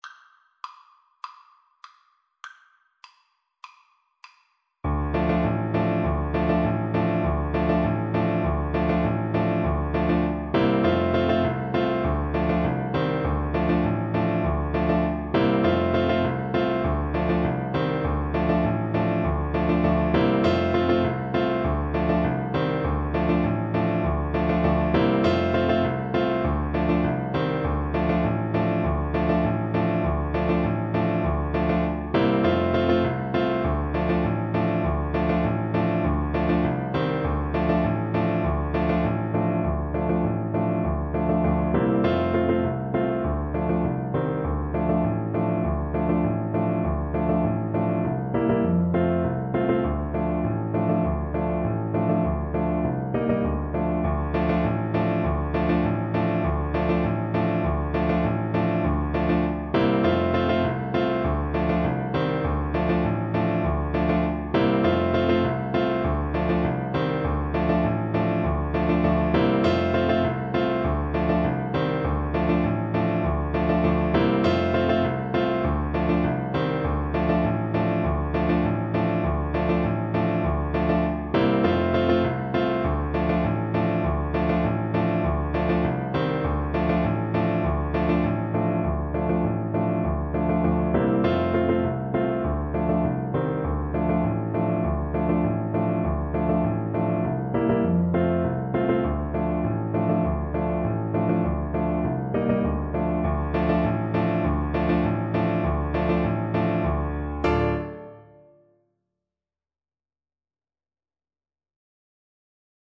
World Trad. Ikariotikos (Greek Folk Song) Alto Saxophone version
Moderato